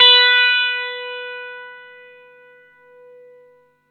R12NOTE B +2.wav